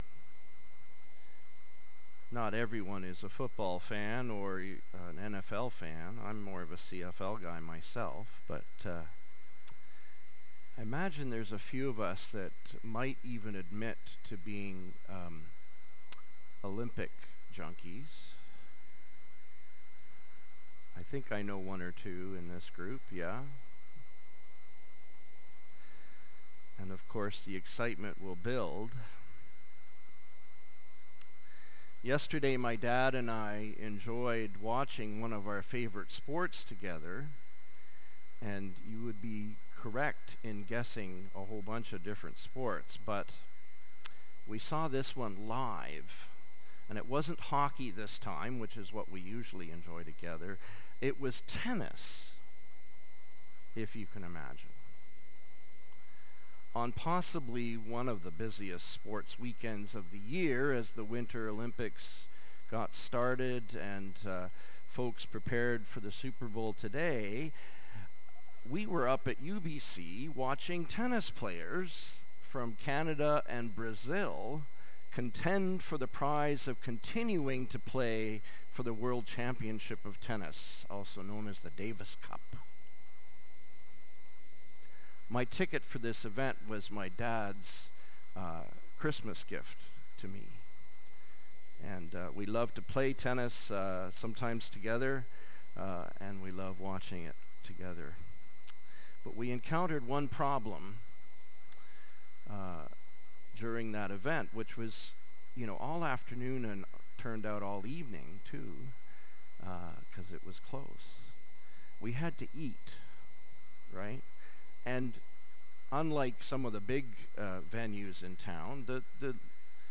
Feb 8, 2026 Sermon - Salt of the Earth